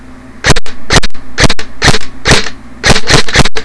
и без оного.